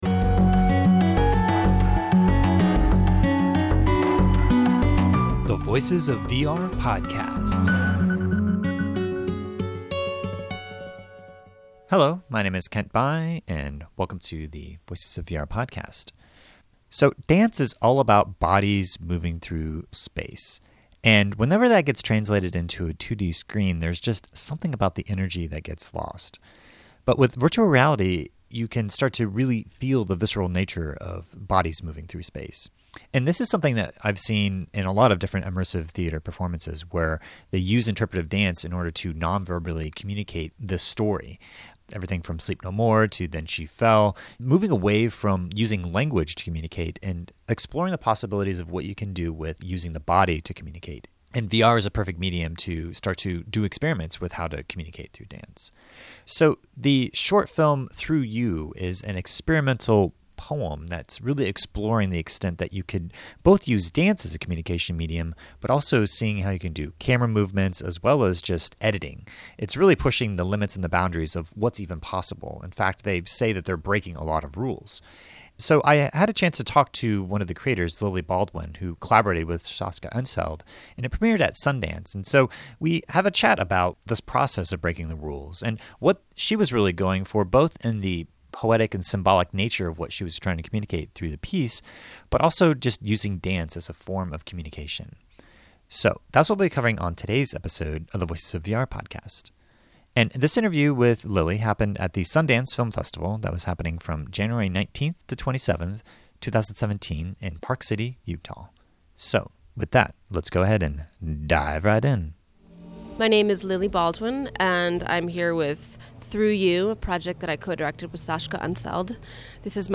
at the Sundance Film Festival